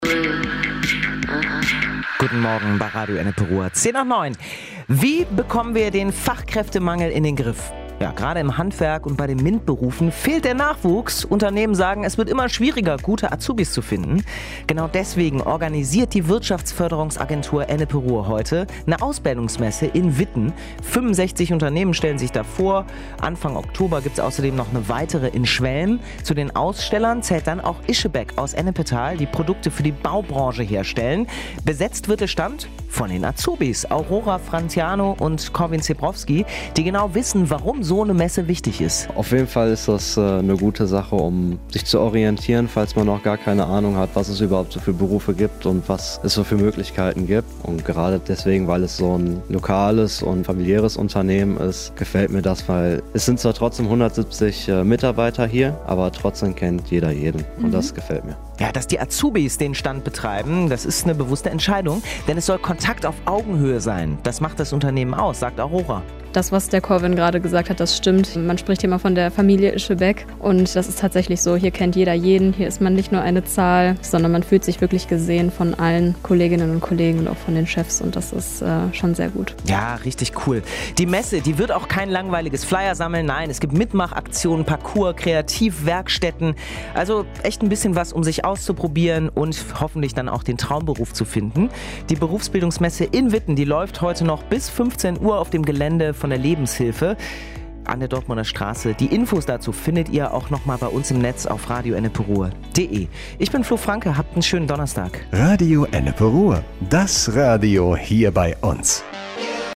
Beitrag Radio Ennepe Ruhr vom 10.09.2025